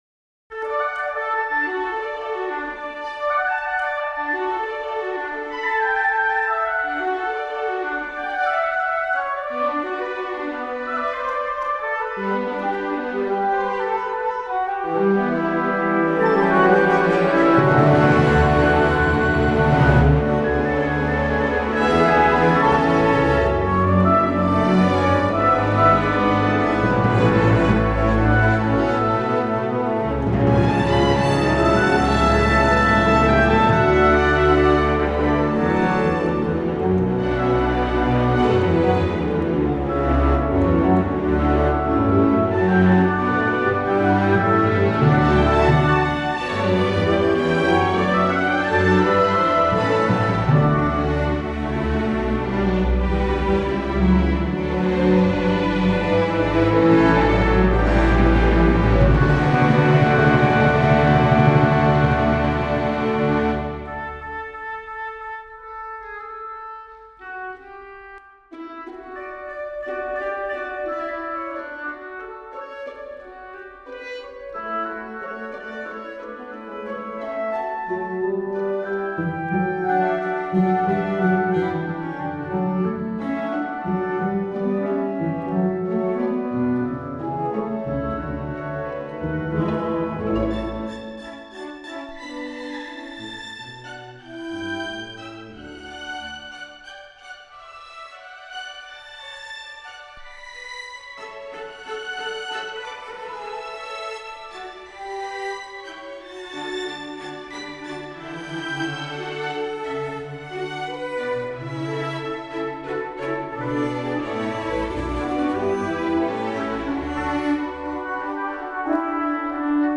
Trascrizione per Grande Orchestra dall’originale per Organo